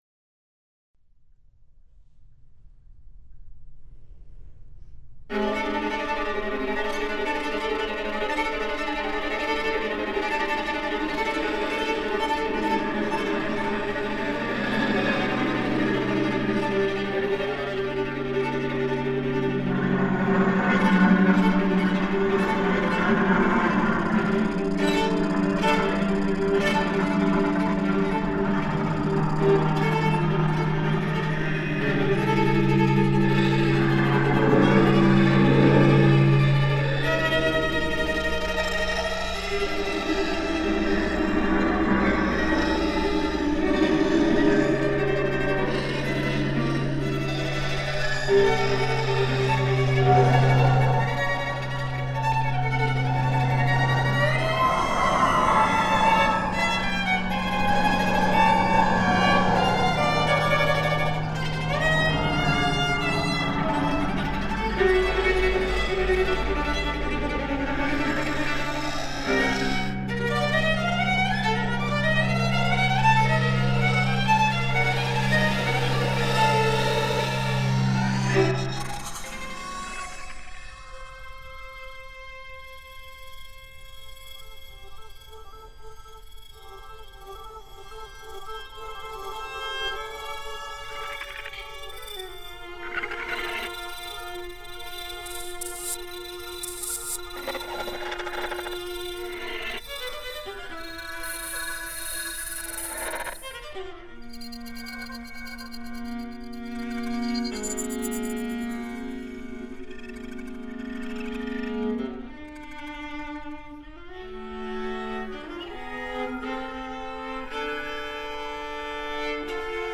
For Viola and 5.1 surround sound